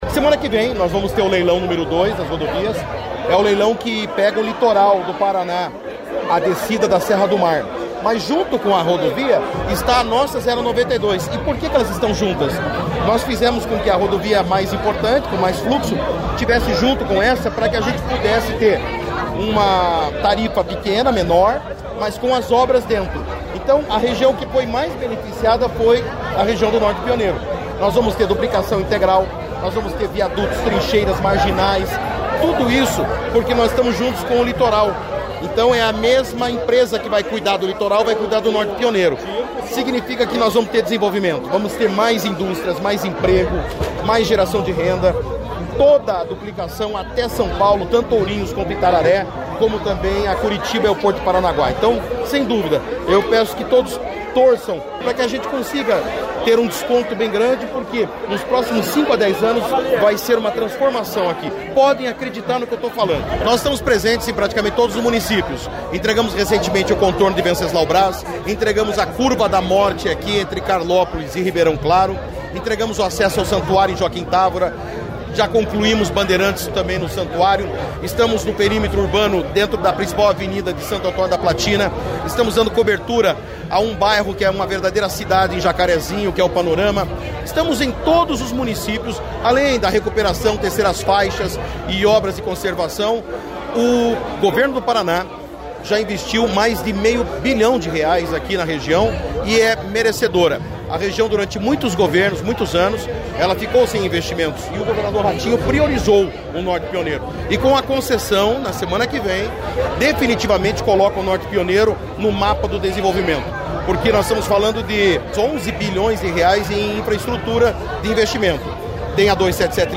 Sonora do secretário de Infraestrutura e Logística, Sandro Alex, sobre a entrega da obra de duplicação da PR-092 em Siqueira Campos